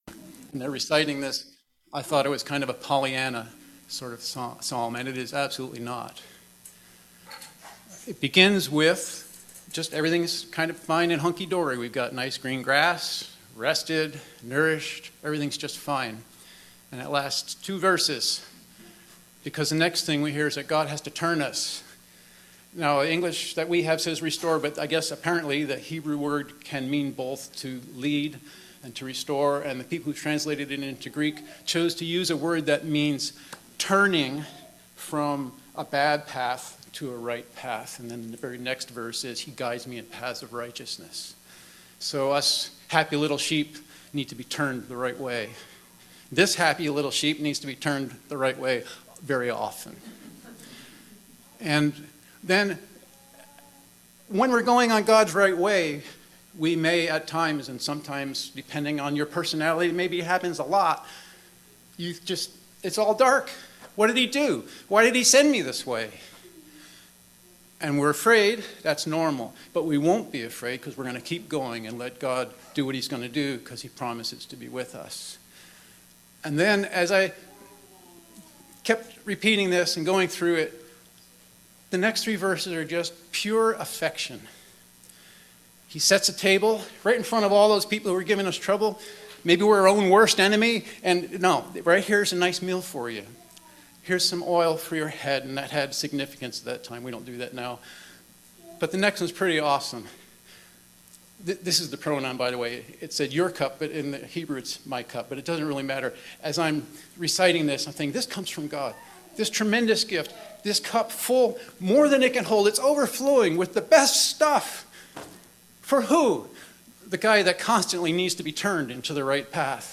Sermons | New Song Community Church